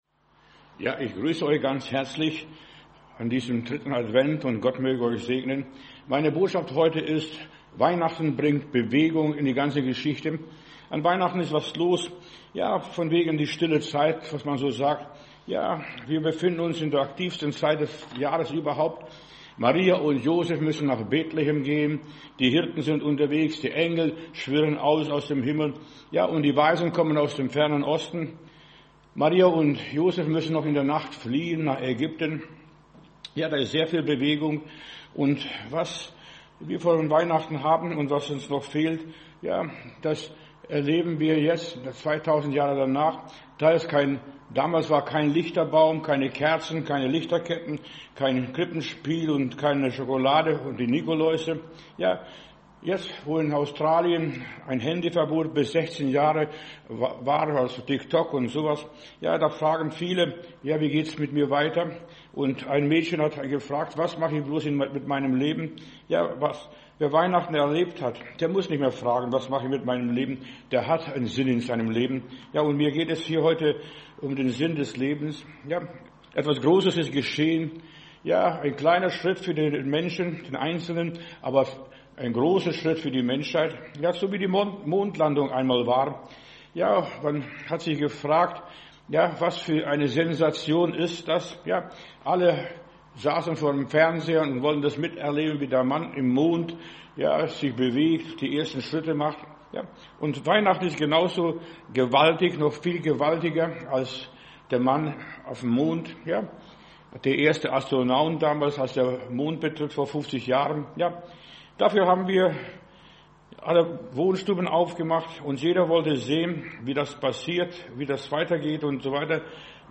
Predigt herunterladen: Audio 2025-12-14 Weihnacht bringt Bewegung Video Weihnacht bringt Bewegung